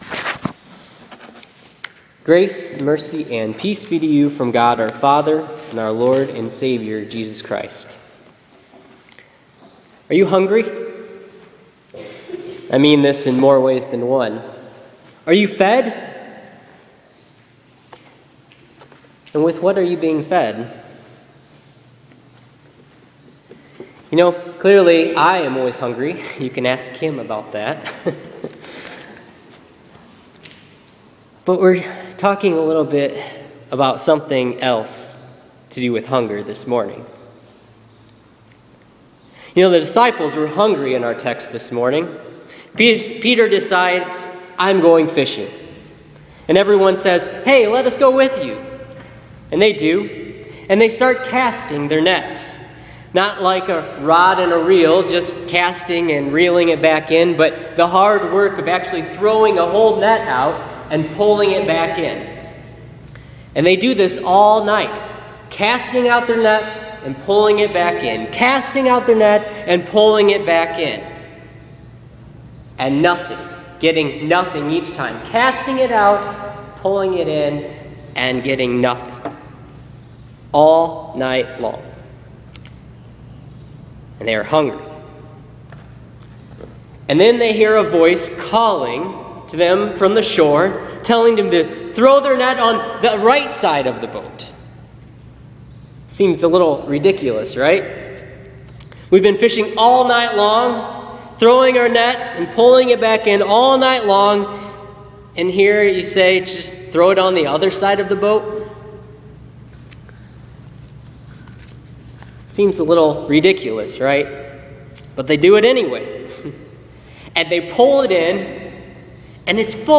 Listen to this week’s sermon on John 21:1-14 for the 3rd Sunday of Easter. The risen Jesus appears before His disciples on the shore of Galilee.